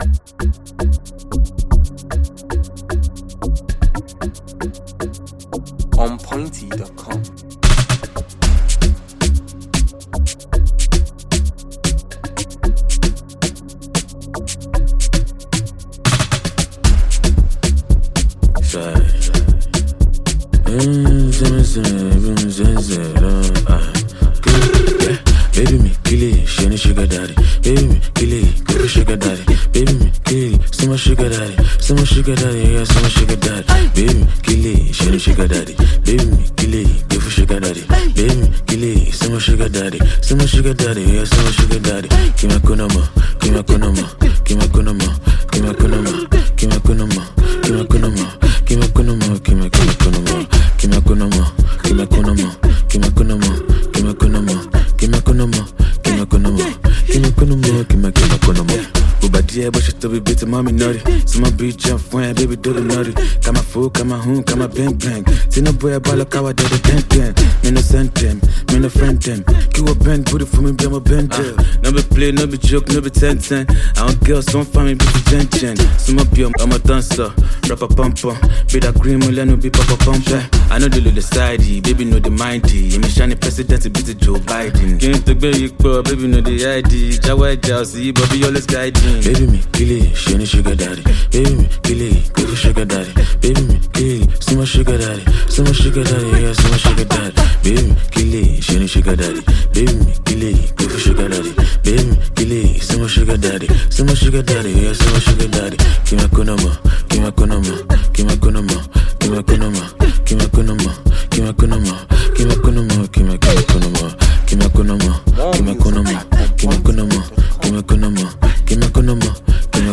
it will keep you on the dancefloor